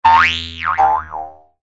toonbldg_settle.ogg